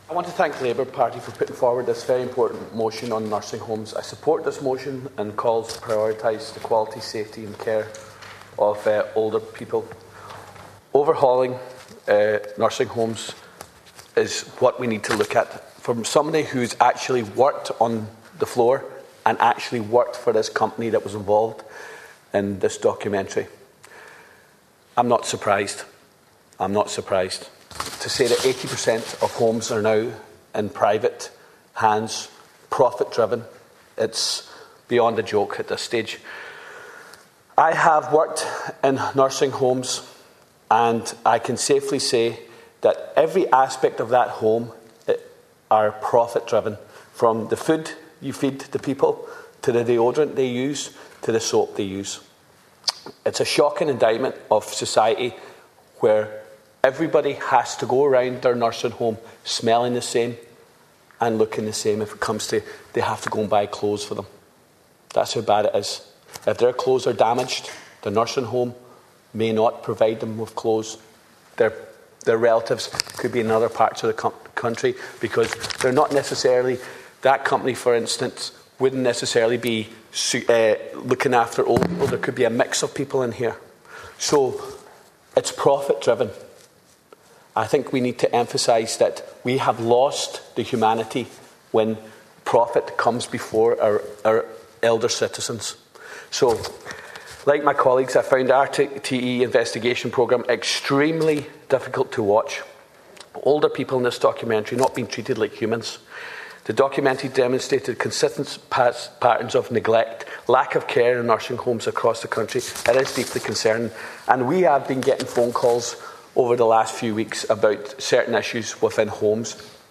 Ward outlines his own experiences working in nursing homes during Dail debate
Speaking on a Labour Party motion on the future nursing homer provision, Deputy Charles Ward outlined his experience as a healthcare worker in a dementia unit.
You can  hear Deputy Ward’s full contribution here –